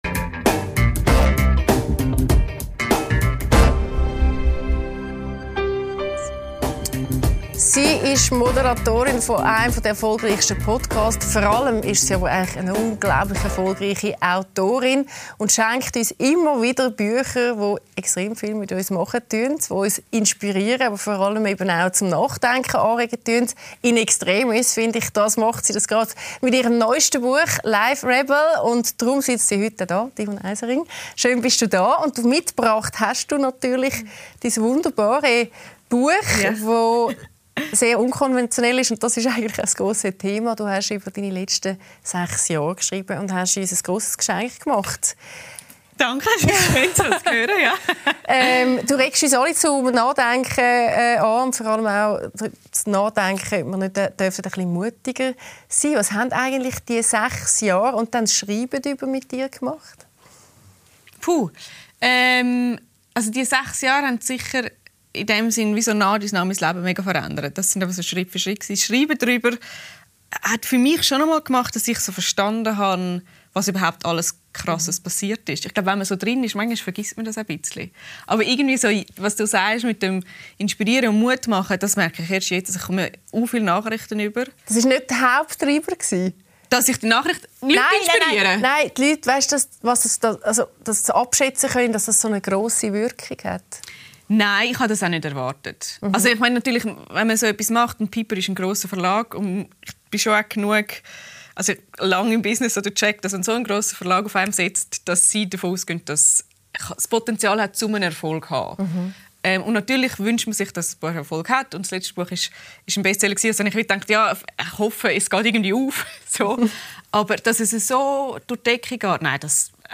LÄSSER ⎥ Die Talkshow